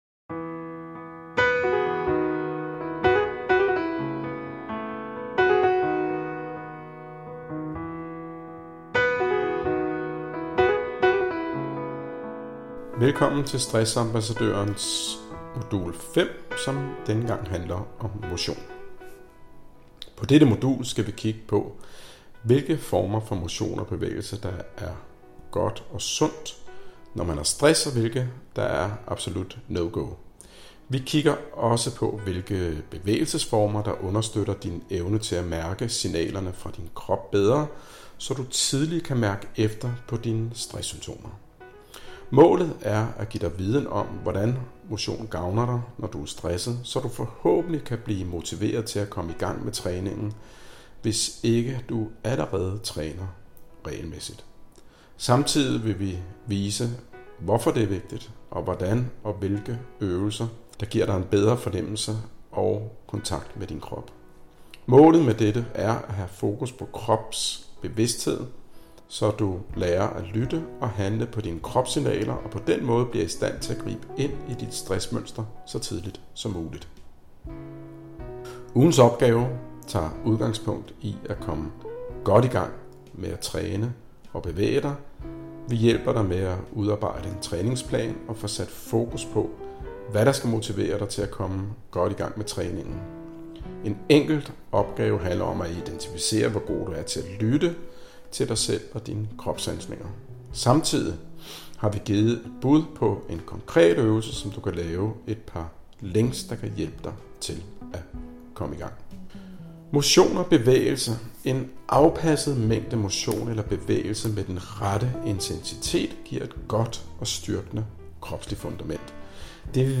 Indlaest-Artikel-Modul-5-Motion-og-kropssansninger-Indlaest-artikel.mp3